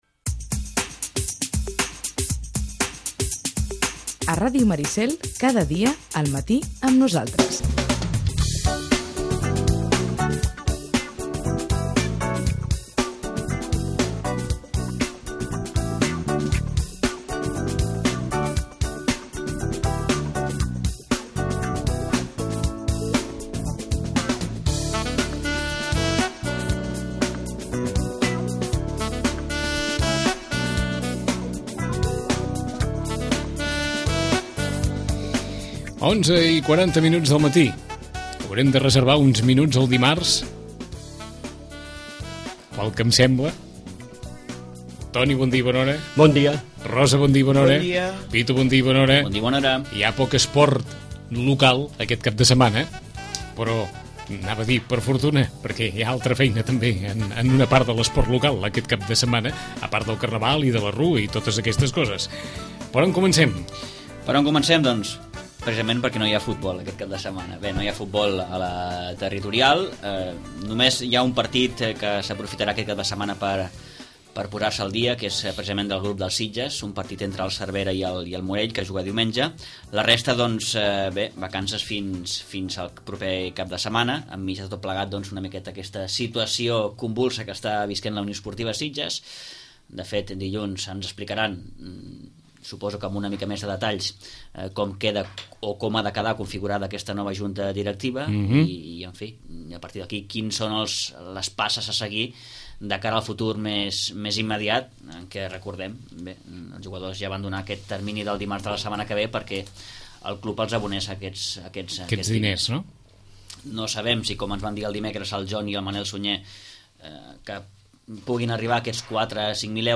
Tertúlia esportiva